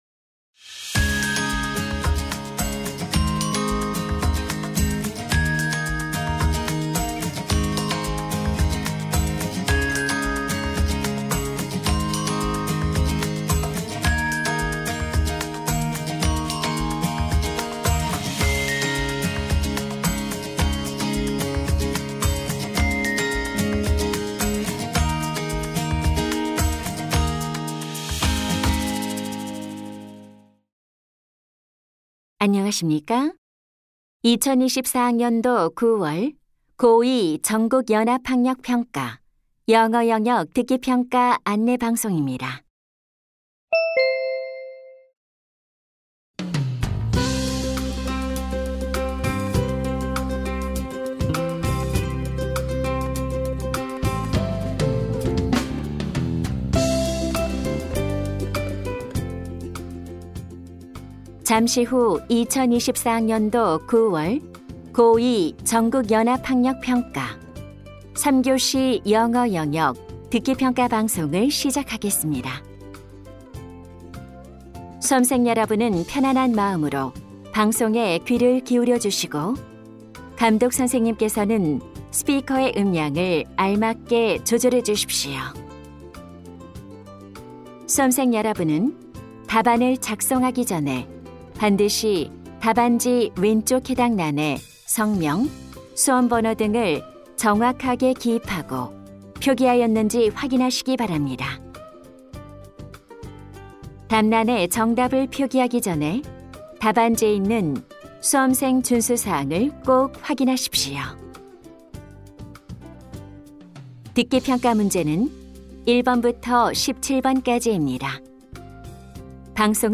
2025년 8월 16일자 고1,2 모의고사 듣기 파일 첨부파일 If you really want to do something, you'll find a way.